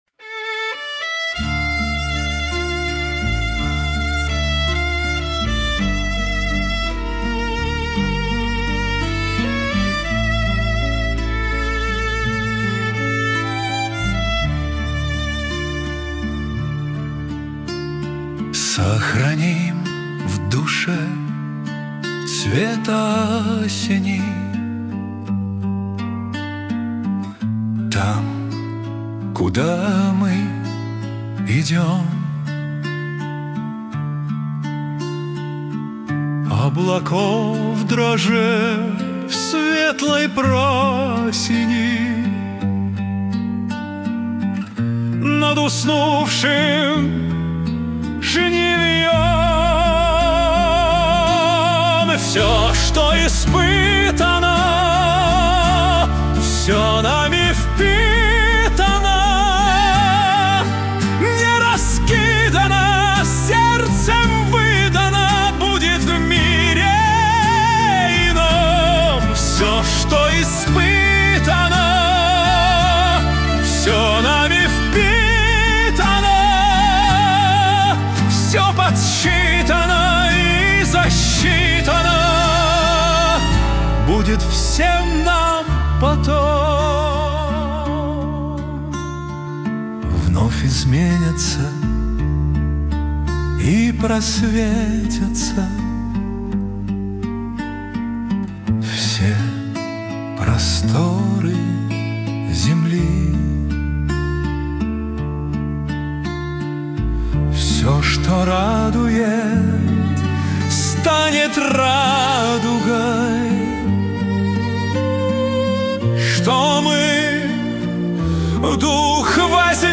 кавер-версия
Для Медитаций